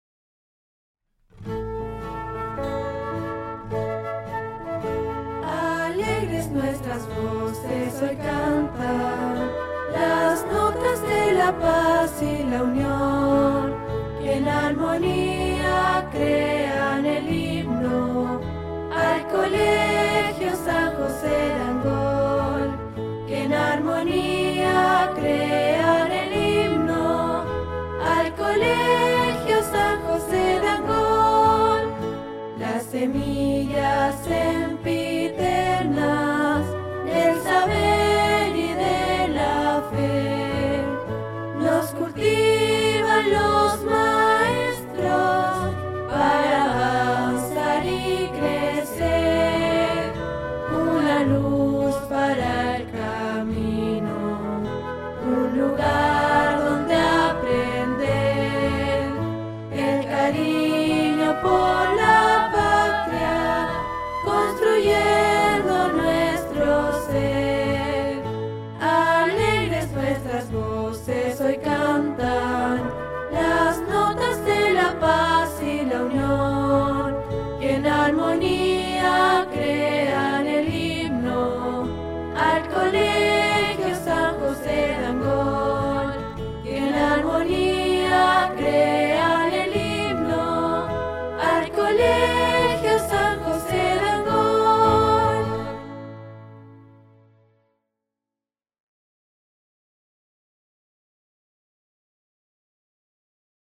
Himno | Colegio San José Angol